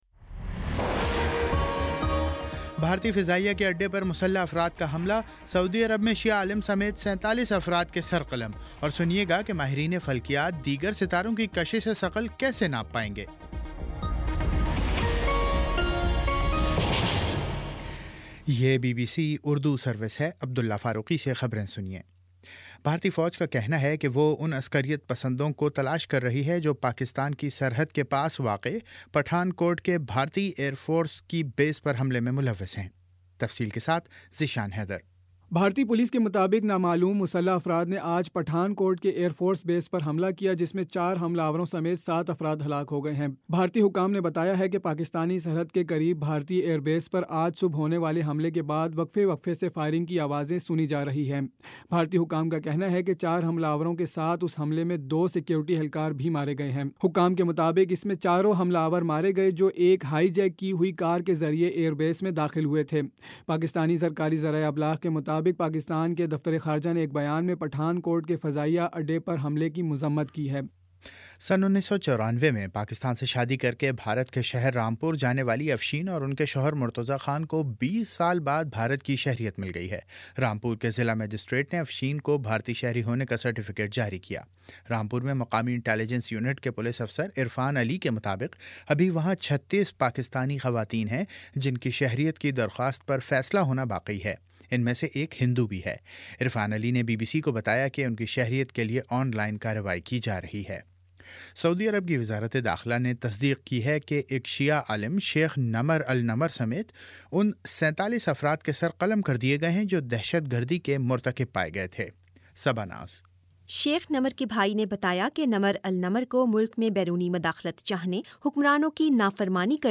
جنوری 02 : شام پانچ بجے کا نیوز بُلیٹن